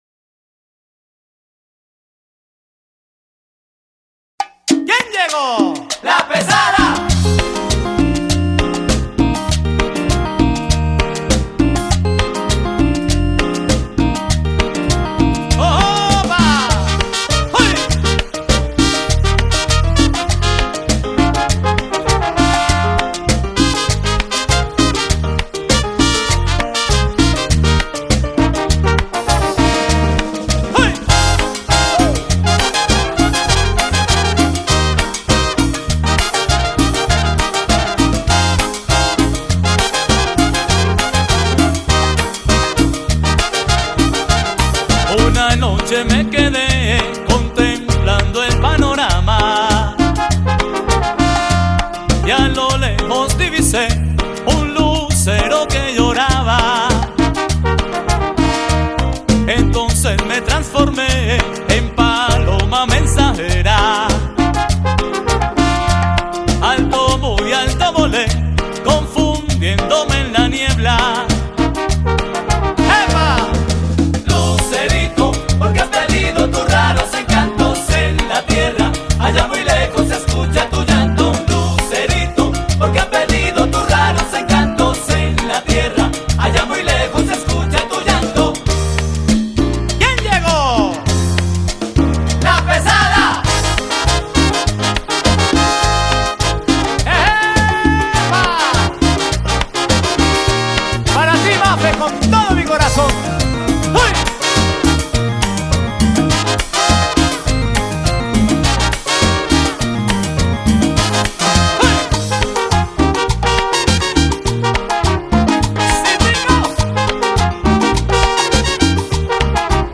orquestas colombianas